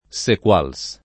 Sequals [ S ek U# l S ] top. (Friuli)